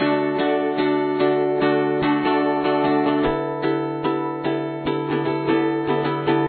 Guitar 1 is tuned to : Open E Tuning (E, B, E, G#, B, E)